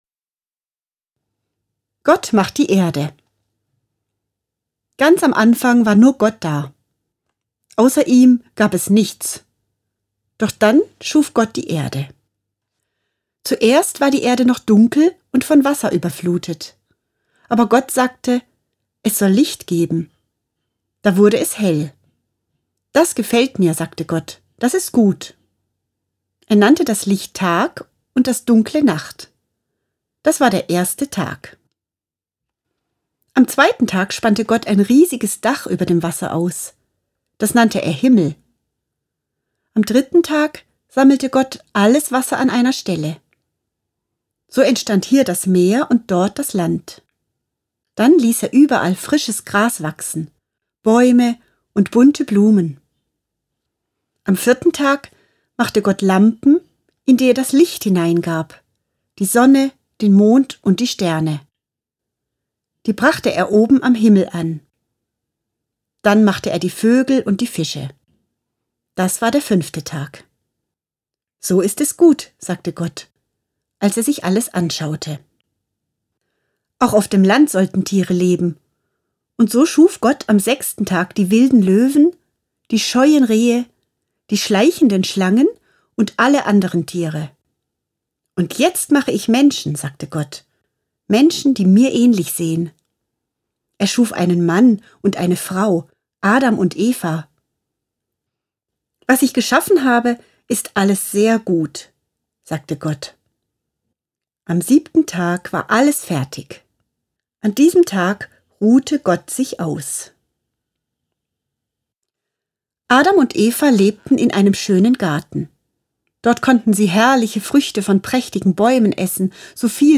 Nach dem überwältigenden Erfolg der erweiterten Buchausgabe erscheint diese wunderbare Kinderbibel jetzt auch als Hörbuch.